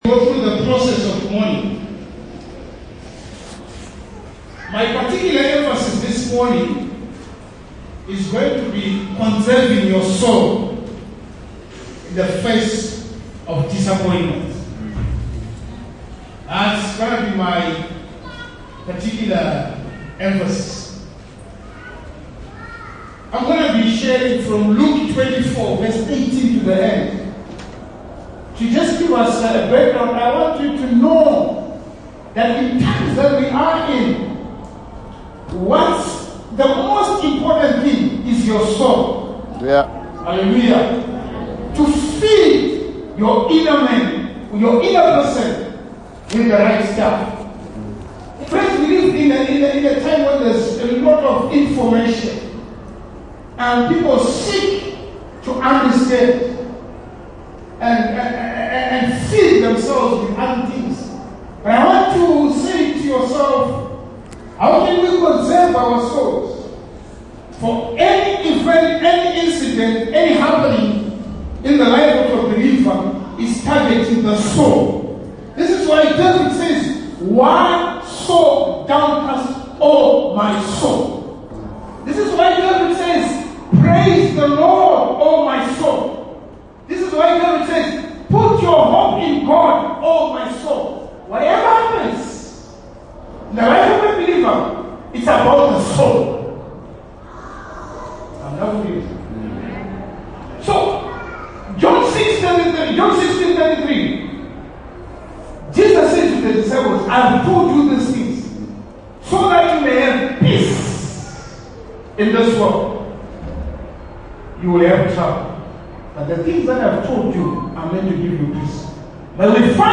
Sermons | Barnabas Leadership Outreach Center